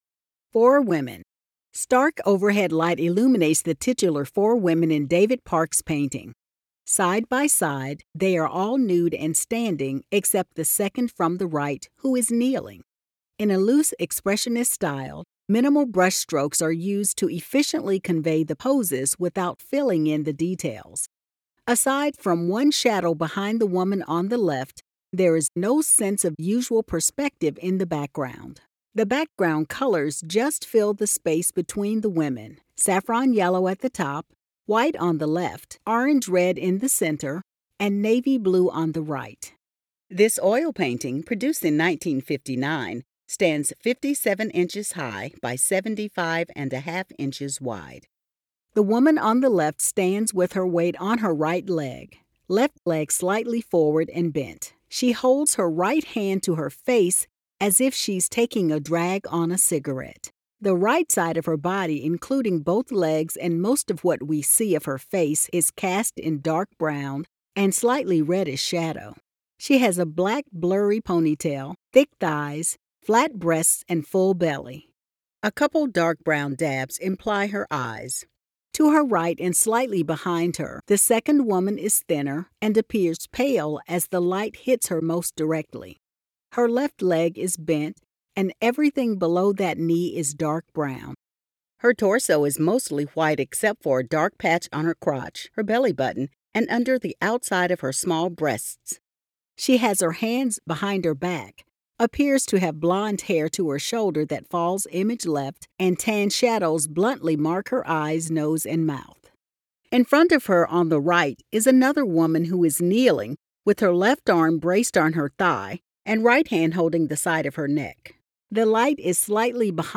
Audio Description (02:57)